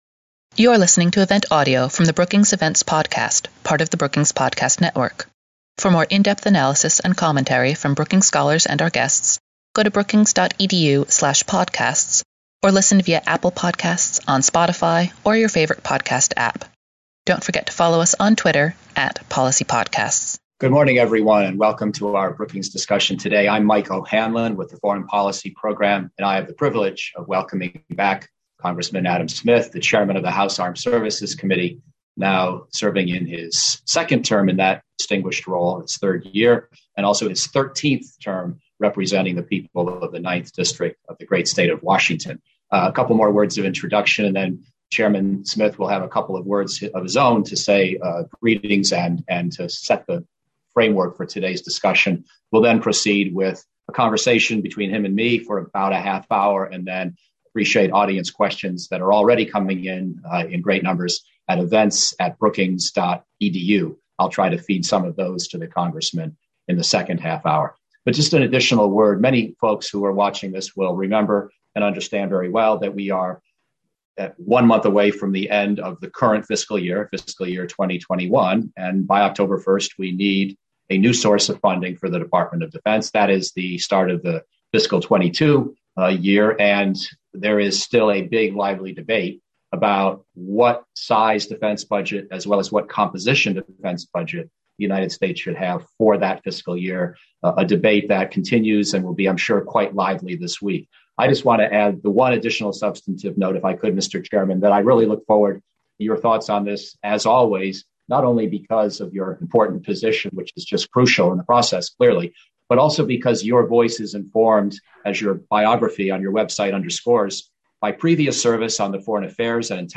Questions from the audience followed.